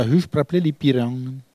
Elle crie pour appeler les oisons
Maraîchin